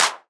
Clap Funk 6.wav